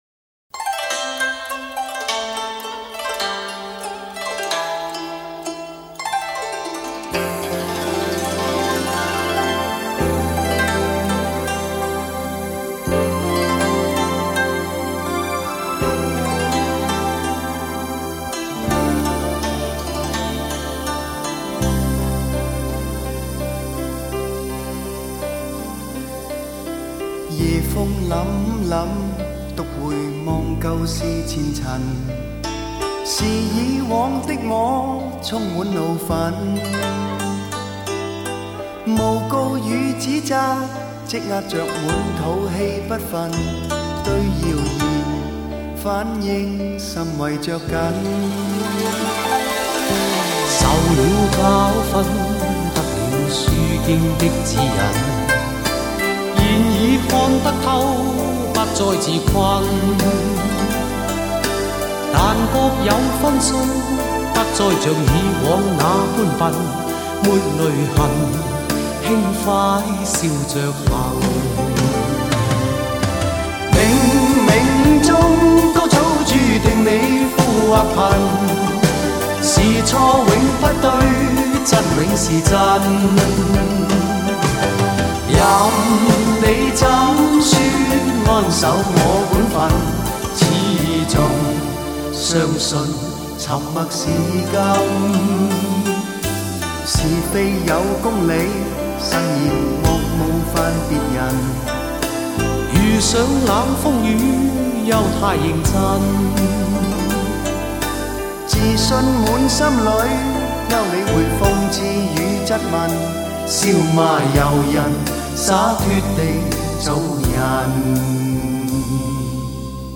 雄伟典范乐曲 必唯天作之合HI-FI典范 极致人声
精心炮制的发烧级示范天碟